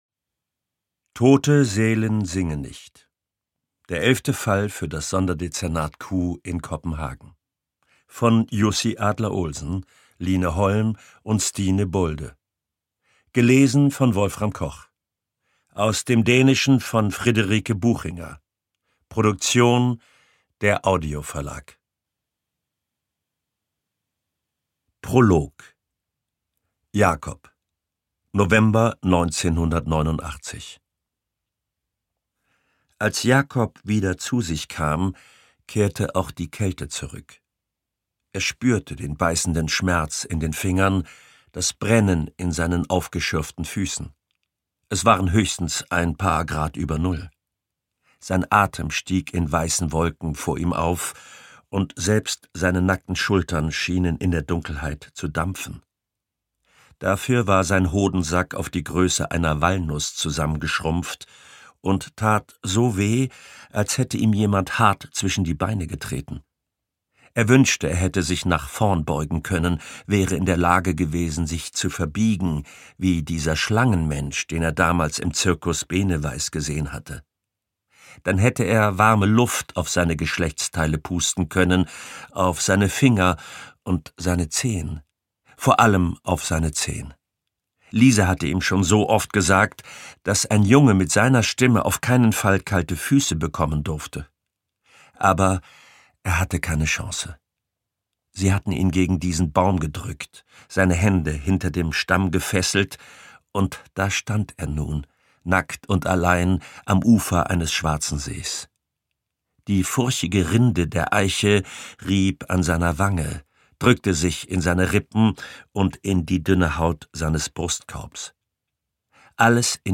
Ungekürzte Lesung mit Wolfram Koch (2 mp3-CDs)
Wolfram Koch (Sprecher)